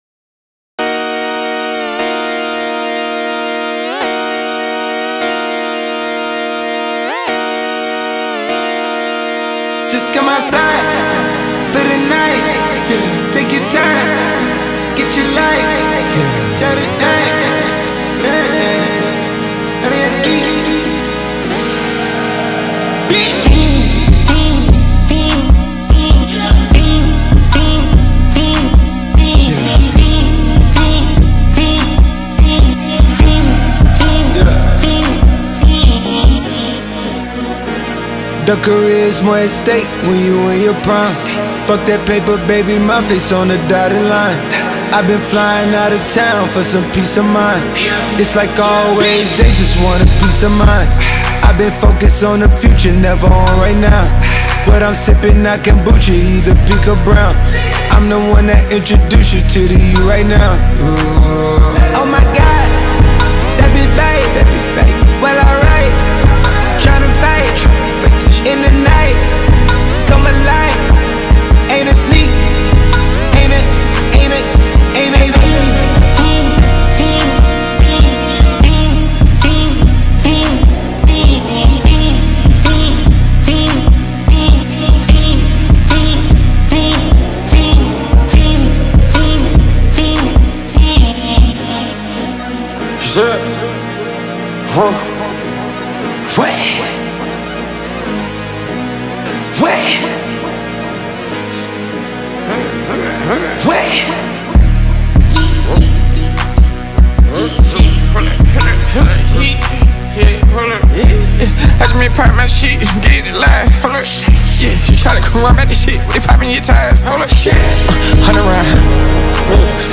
2024-10-17 16:09:50 Gênero: Hip Hop Views